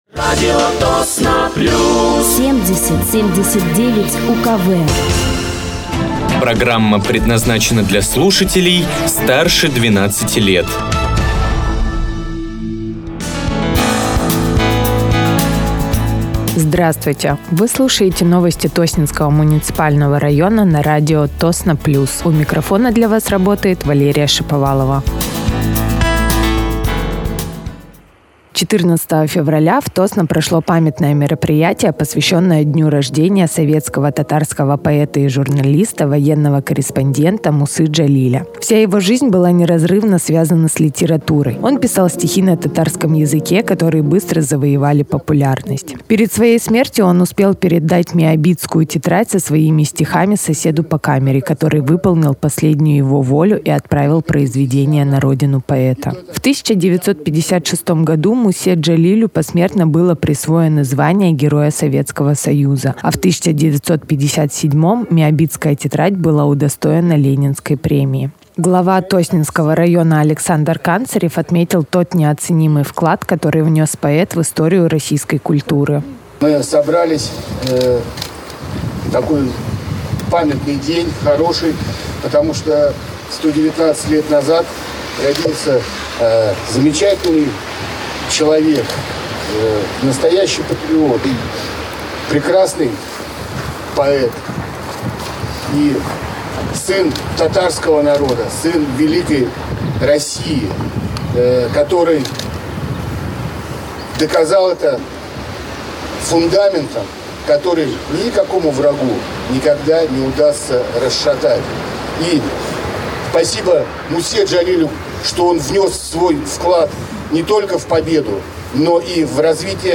Вы слушаете новости Тосненского муниципального района на радиоканале «Радио Тосно плюс».